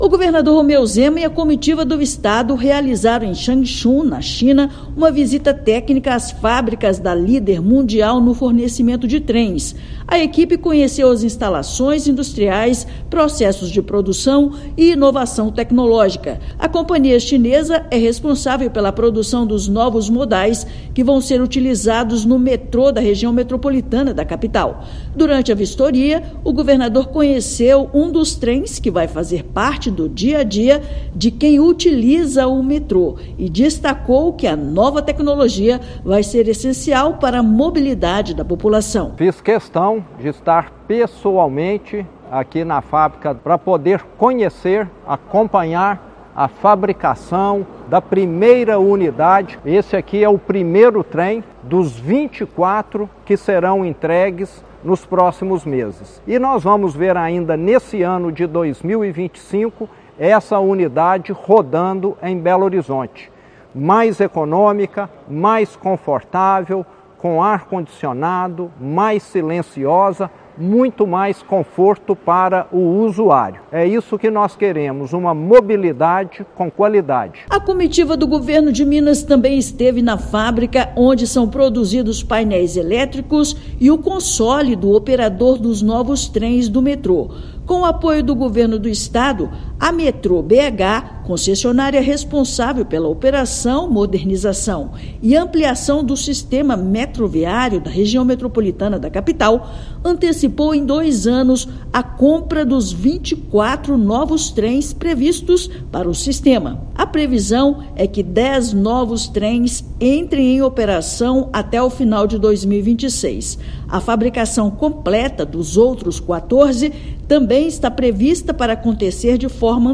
[RÁDIO] Na China, Governo de Minas conhece fábrica dos novos trens do Metrô da RMBH
Previsão é que dez das 24 composições entrem em operação já até o fim de 2026. Ouça matéria de rádio.